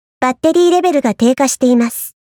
ShiroTsubaki_B_Battery Low.mp3